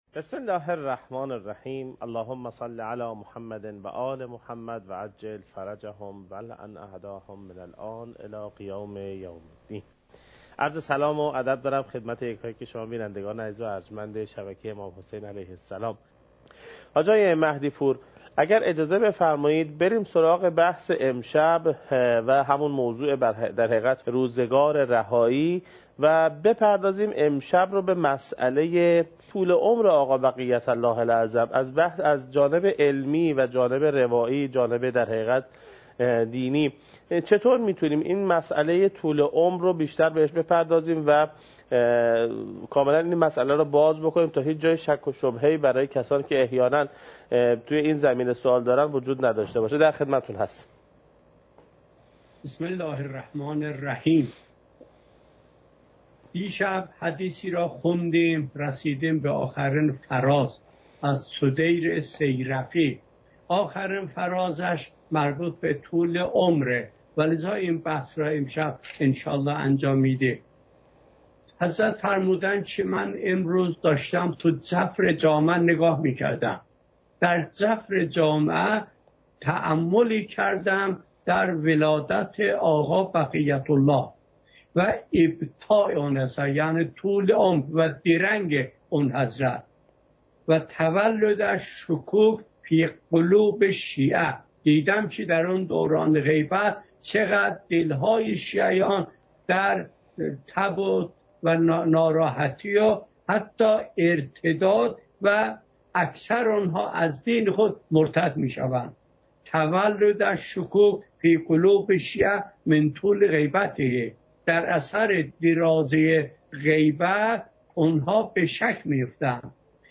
مکان: کربلا